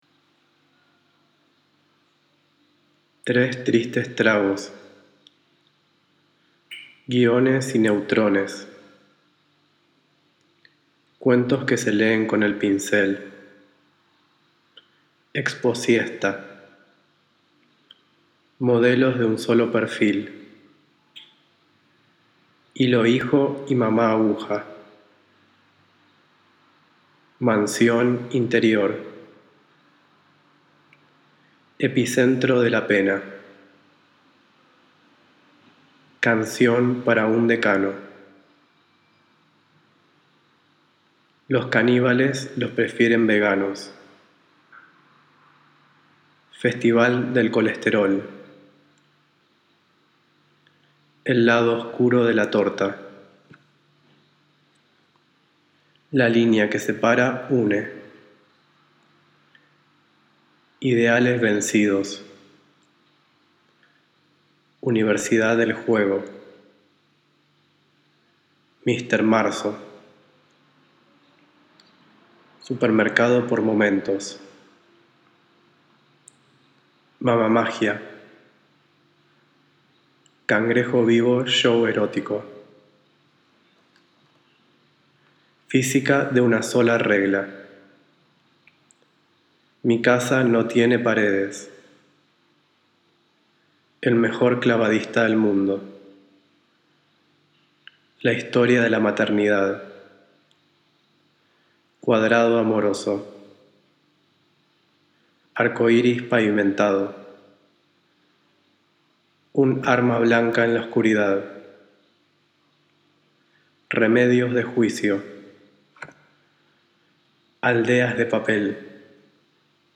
Simultaneously, I recorded myself reading them into a vinyl that was available for blind visitors and played at the exhibit as part of the public program called ‘If the eye could touch’.